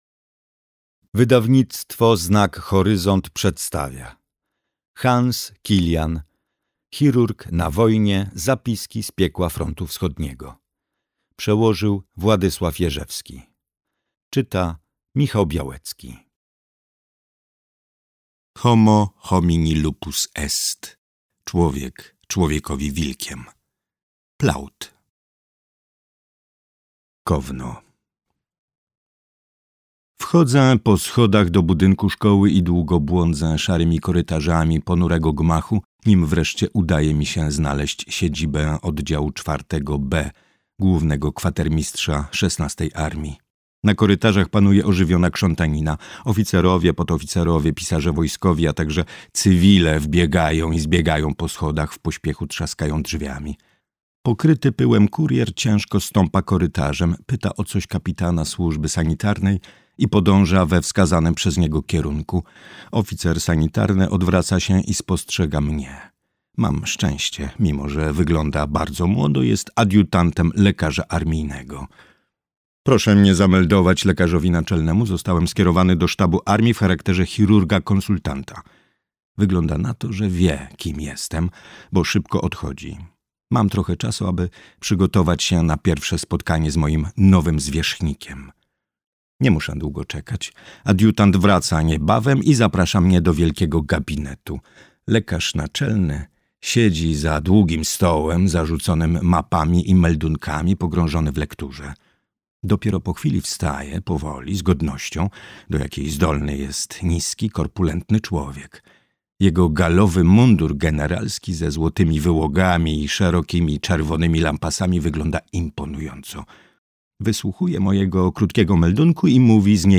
Chirurg na wojnie. Zapiski z piekła frontu wschodniego - Hans Killian - audiobook + książka